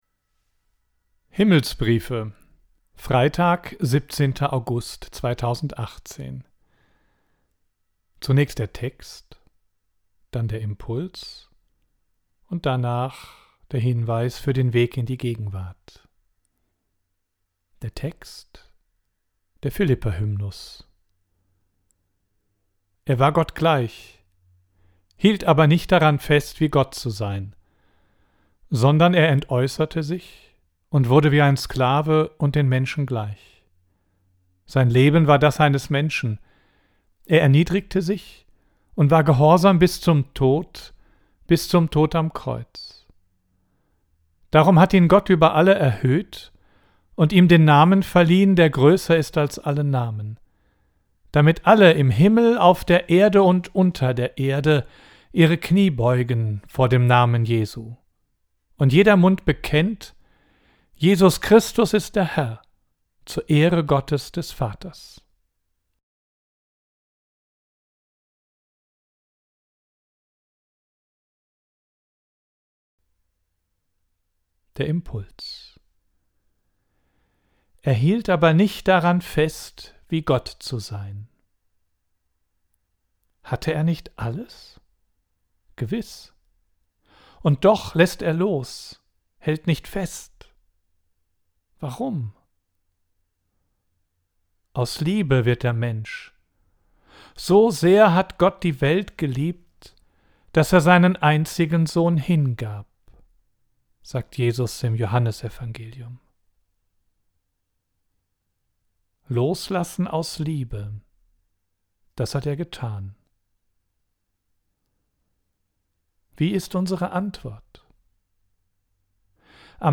Audio: Text und Impuls